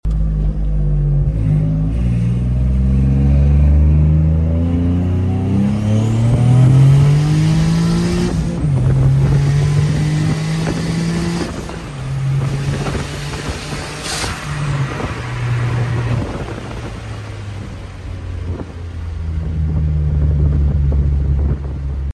Genesis Coupe 3.8T doing some test pulls! Pulls pretty hard for just 5 psi!